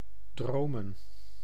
Ääntäminen
Vaihtoehtoiset kirjoitusmuodot (vanhentunut) dreame Synonyymit sweven heaven vision envision lulu Ääntäminen GenAm: IPA : /ˈdɹim/ US : IPA : [dɹim] Tuntematon aksentti: IPA : /ˈdɹiːm/ GenAm: IPA : [d͡ʒɹim]